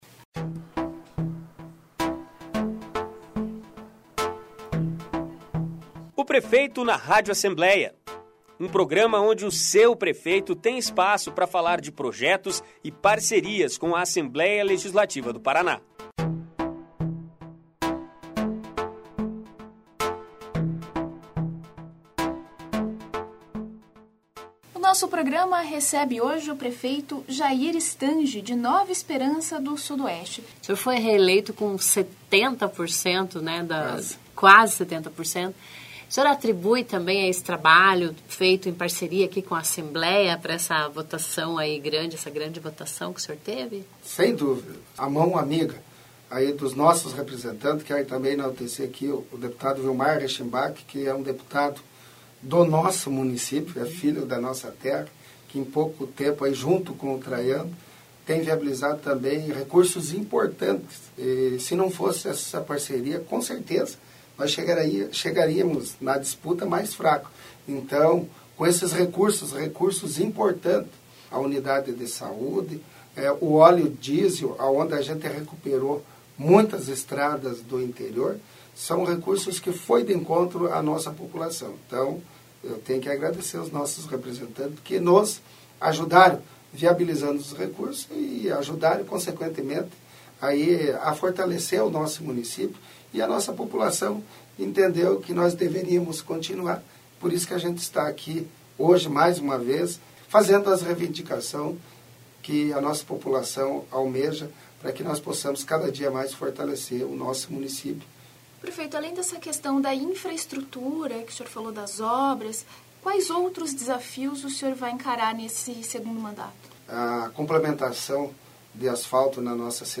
Ouça na íntegra a entrevista com Jair Stange, de Nova Esperança do Sudoeste  no programa  "Prefeito na Rádio Alep".